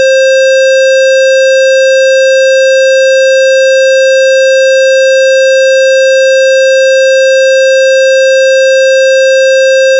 周波数は530Hzで、波形は以下のような感じです。
530Hzの矩形波 (アンチエイリアス適用済)
音の高さは渋谷駅のブザーに近いけれど、雰囲気が本物とは違うようです。